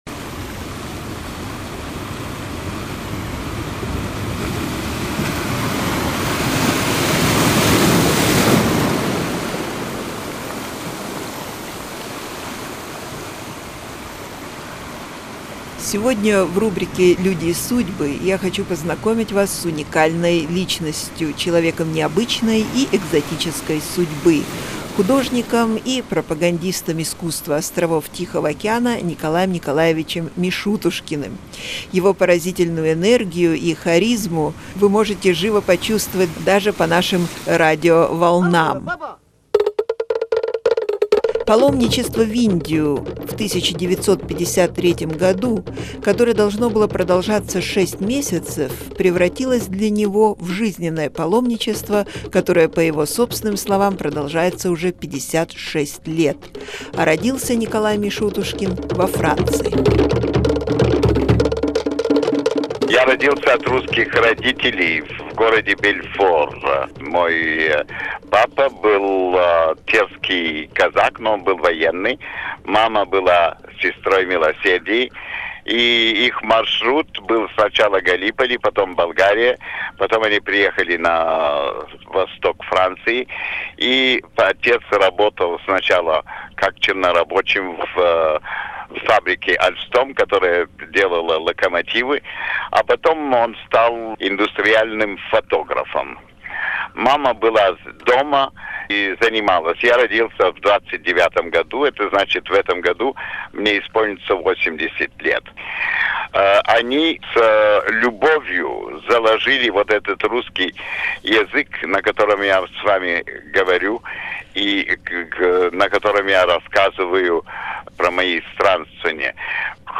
We spoke to him on a number of occasions and he shared with us many of his incredible life experiences.